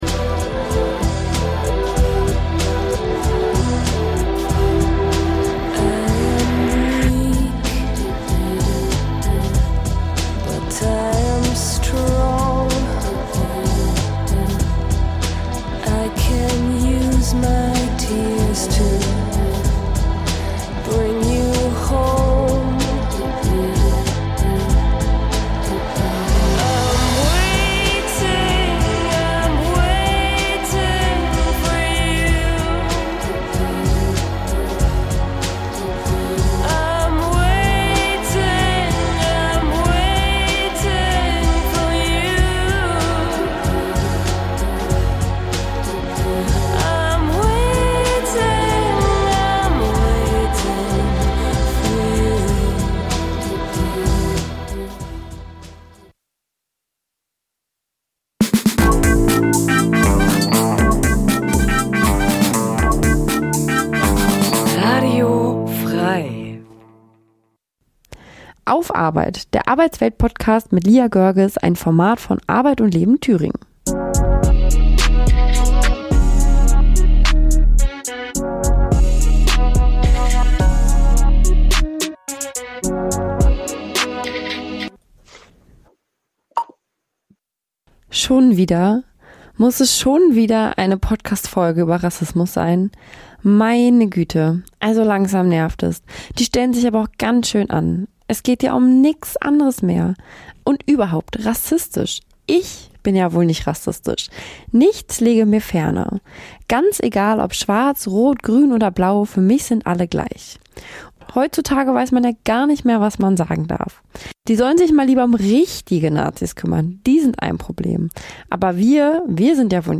Ich will lieber erfahren � mit euch! Expterten zu diesen Themen befragen.